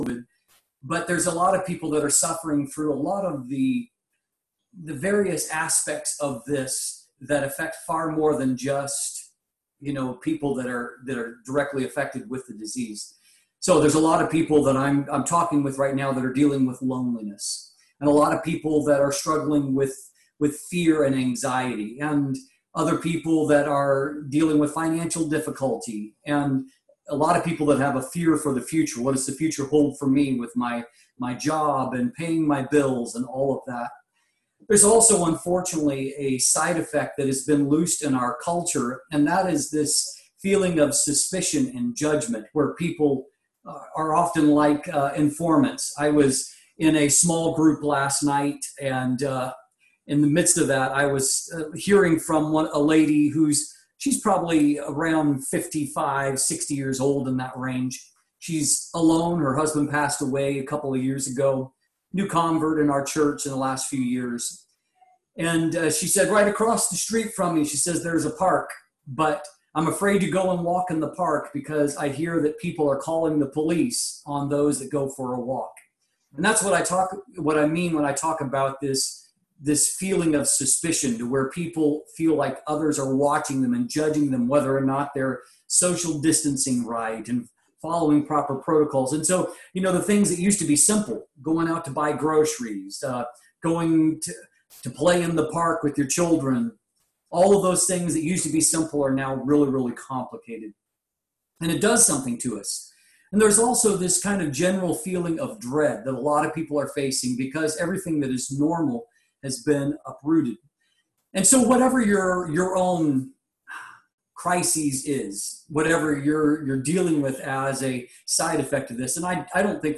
Sermons | Sudbury United Pentecostal Church